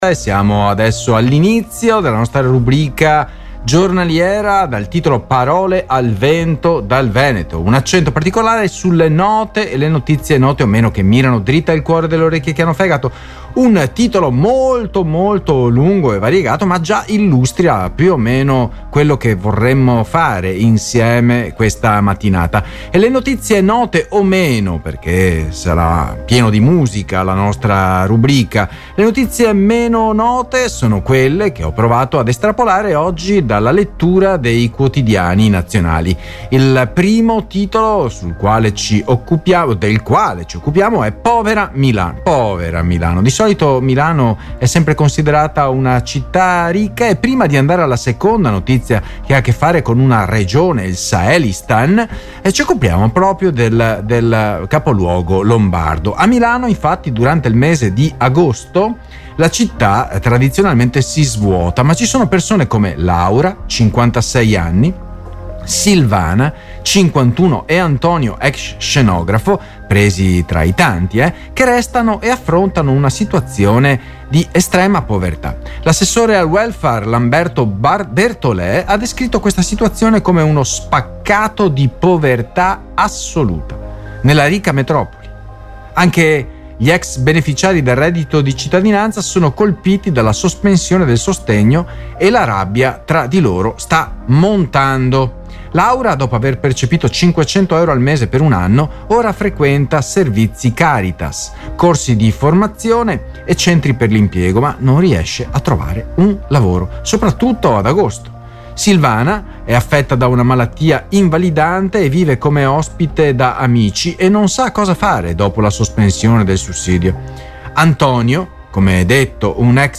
Le notizie oggi: Povera Milano Sahelistan La povertà riguarda tutti Fuga dalla RSA La festa non è in spiaggia Intervista a un rapper particolare Podcast: Apri in un’altra finestra | Download (Duration: 17:38 — 24.2MB)